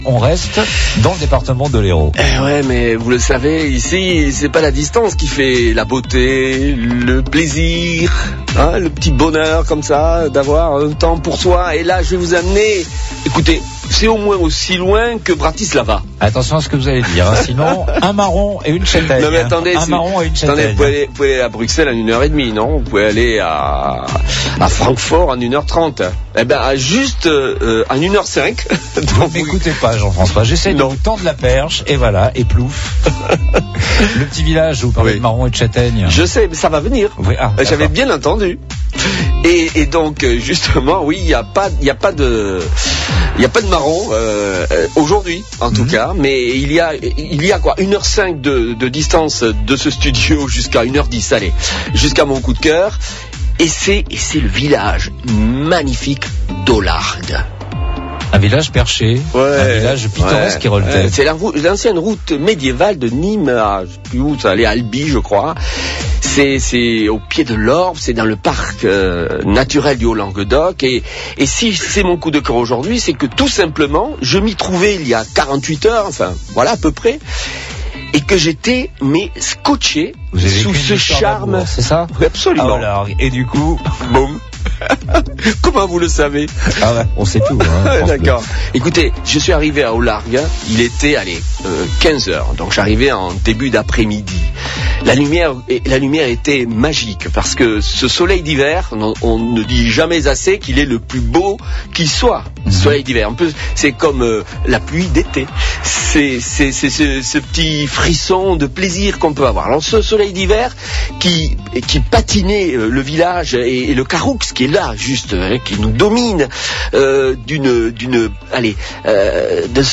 L'émission Samedi de Partir sur France Bleu Hérault met Olargues à l'honneur, aujourd'hui. Un des plus beaux villages de France, la vallée du Jaur, le Caroux, et le Parc Naturel du Haut-Languedoc vous attendent.